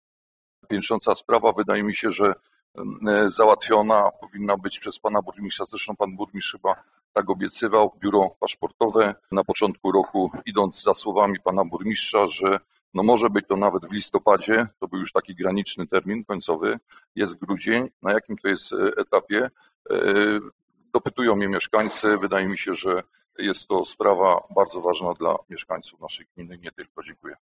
O dalsze kroki związane ze staraniami gminy Wieluń o utworzenie biura paszportowego na terenie miasta pytał na ostatniej sesji Rady Miejskiej radny Radosław Buda: